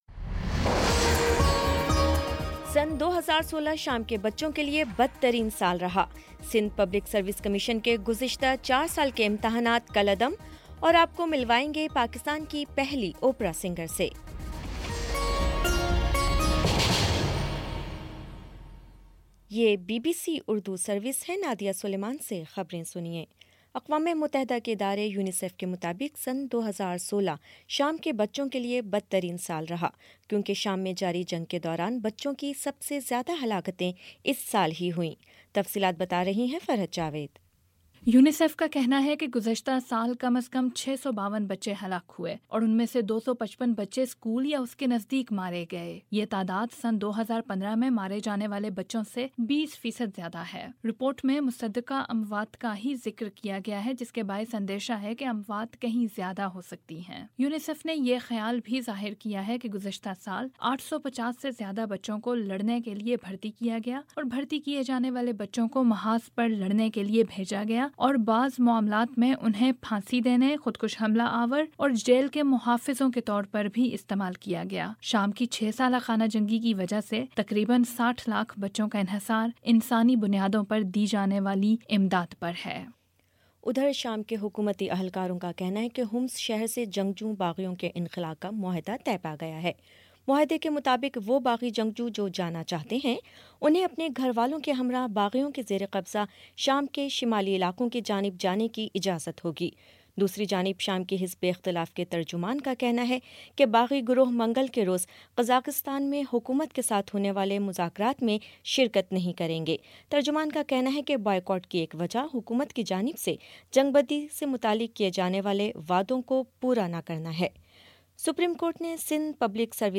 مارچ 13 : شام سات بجے کا نیوز بُلیٹن